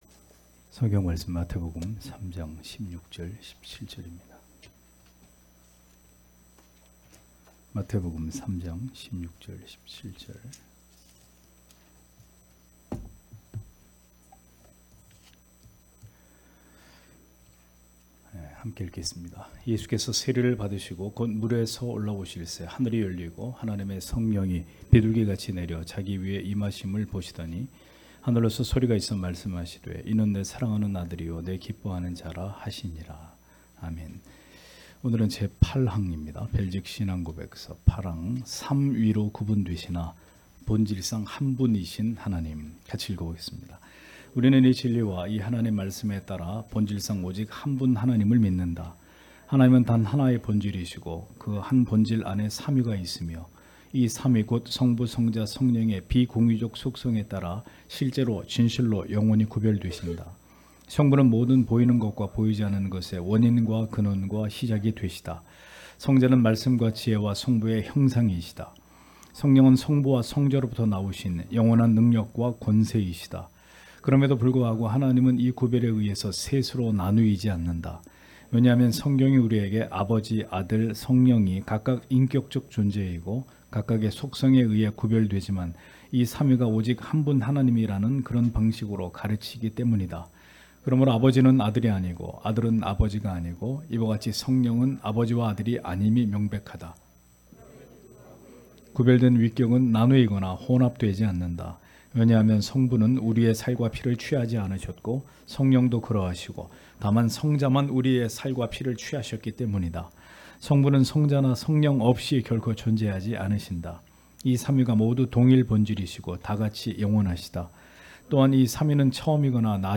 주일오후예배 - [벨직 신앙고백서 해설 8] 제8항 제8항 삼위로 구분되시나 본질상 한 분이신 하나님 (마3:16-17)